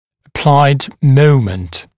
[ə’plaɪd ‘məumənt][э’плайд ‘моумэнт]момент приложенных сил, момент действующих сил, приложенный момент